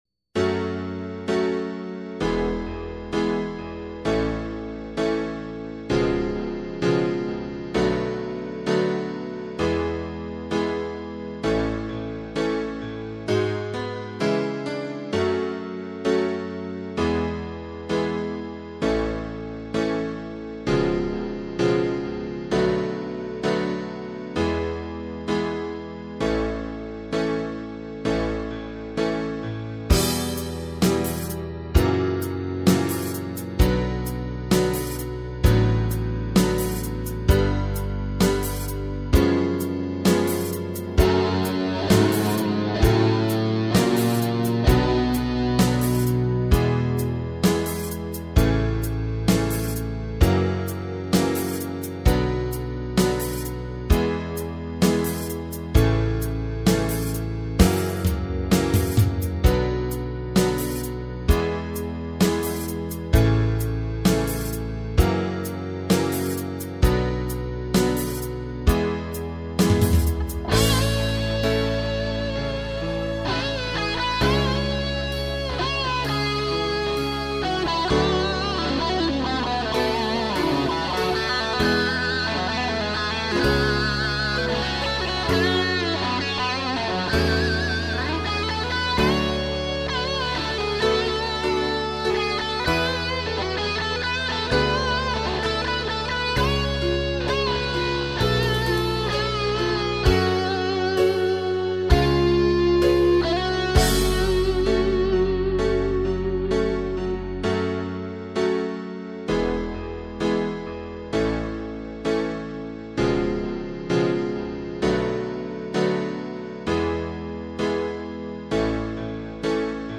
минусовка версия 52141